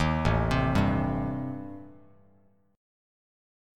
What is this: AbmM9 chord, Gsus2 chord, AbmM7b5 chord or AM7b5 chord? AM7b5 chord